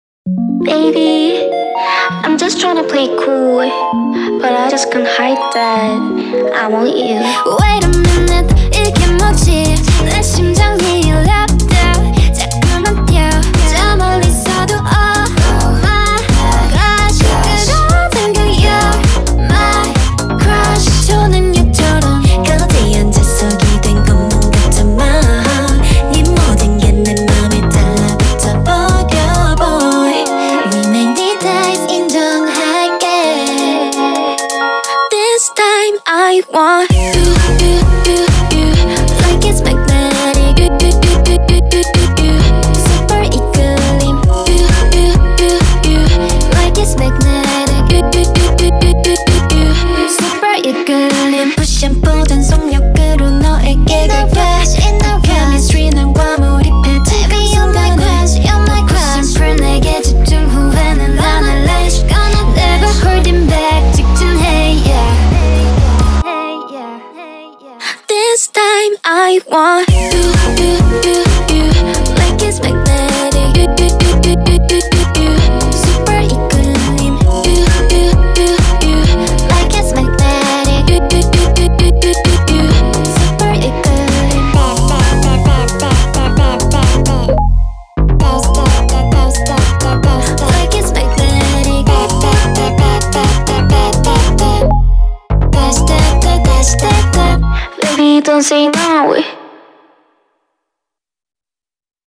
BPM131
Audio QualityPerfect (High Quality)
CommentsHeavy focus on vocal